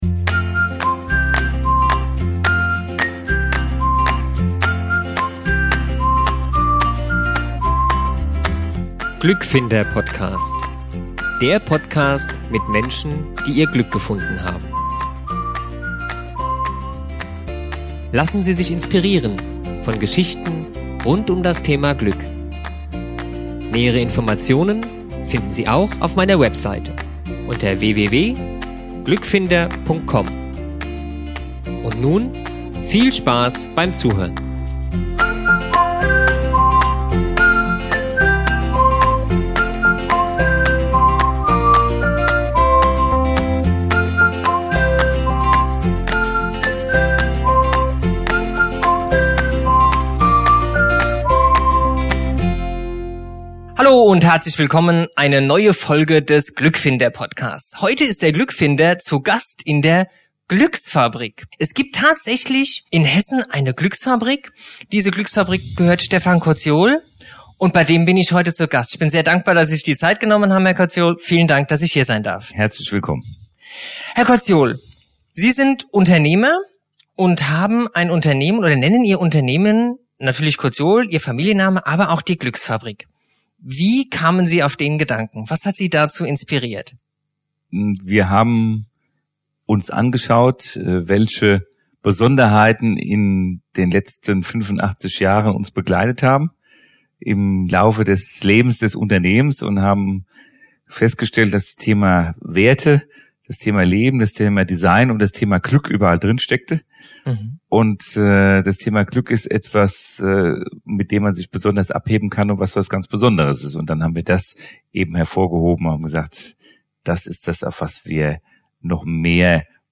Ein schönes Interview mit einem interessanten Menschen.